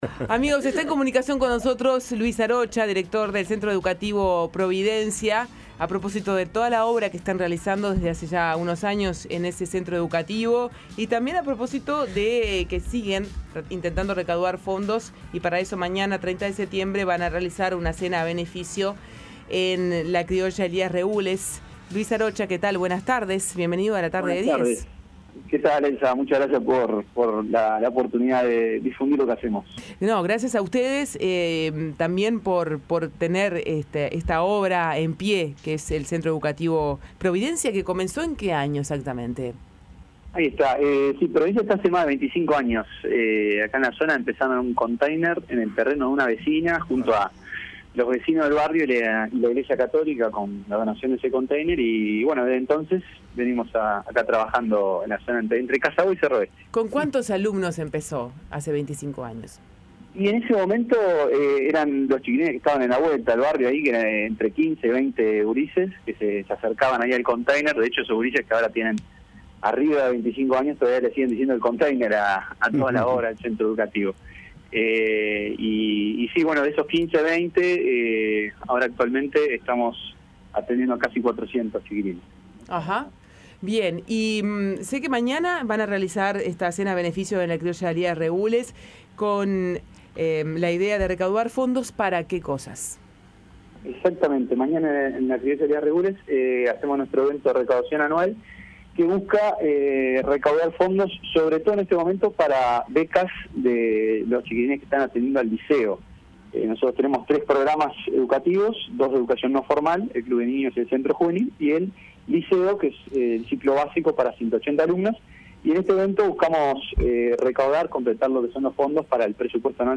El entrevistado explicó que el proyecto educativo, de carácter gratuito, se inició hace 25 años "con unos 15 o 20 muchachos que estaban en la vuelta";, y que en la actualidad se atiende casi a 400 jóvenes.